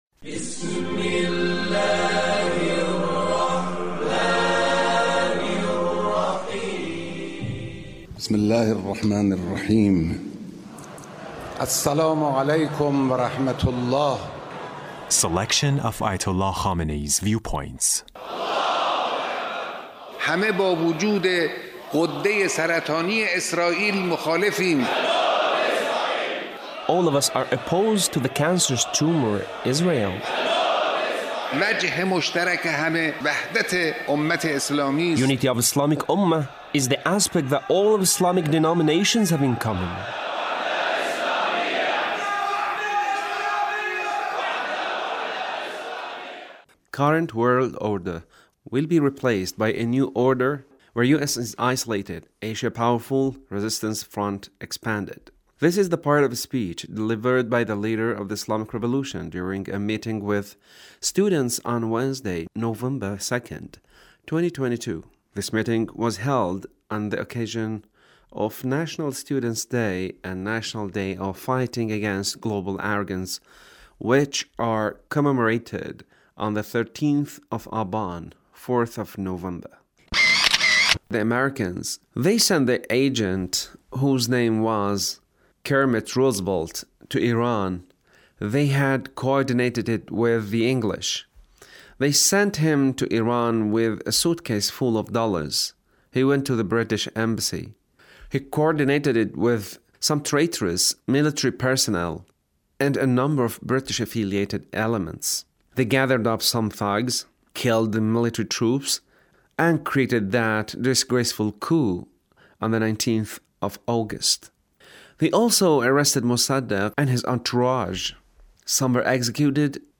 Leader's Speech on 13 th of Aban